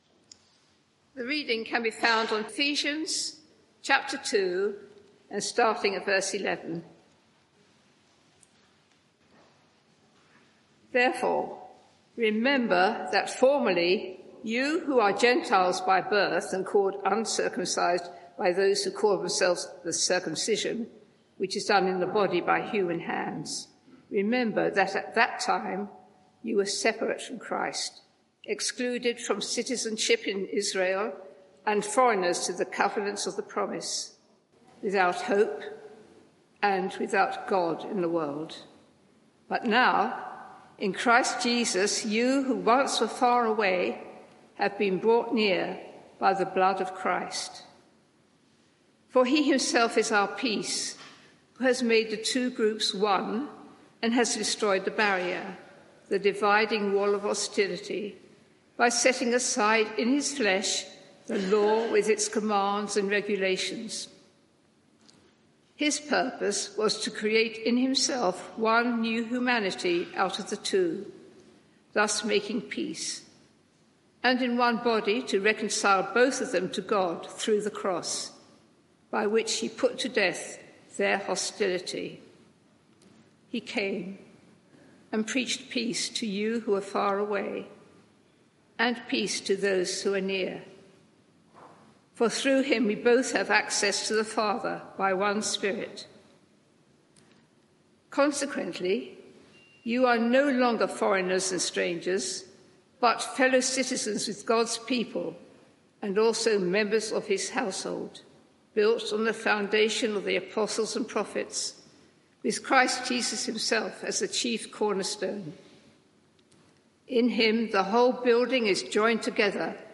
Media for 11am Service on Sun 23rd Jun 2024 11:00 Speaker
Sermon (audio)